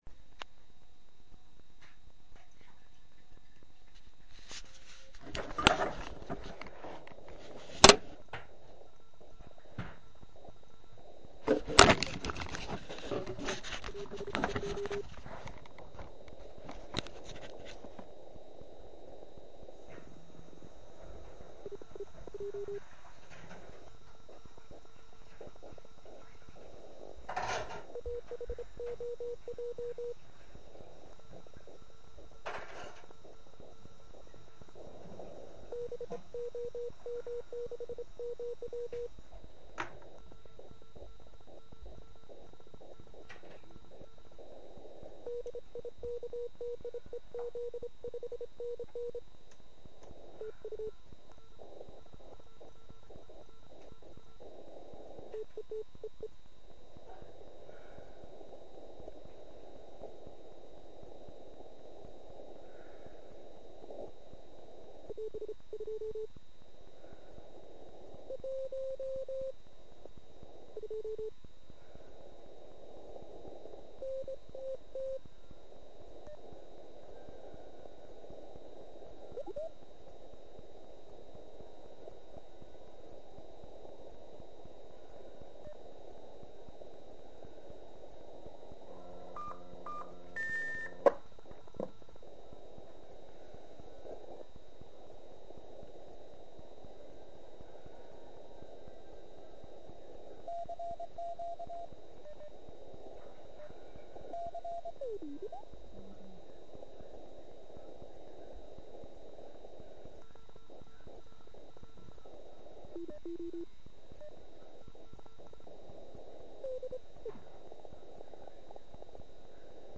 Pokud si chcete poslechnout jak jste u mne byli sly�et, pak zde je z�znam cel�ho z�vodu.
Pak u� jsem nevydr�el a dokonce jsem přestal s RUN m�dem a jen tak jsem projel band.